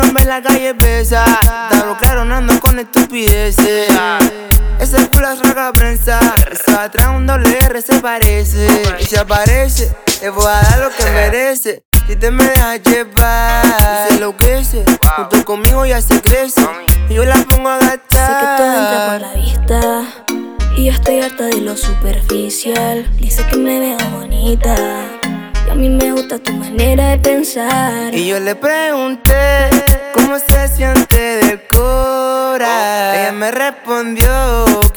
Urbano latino
Жанр: Латино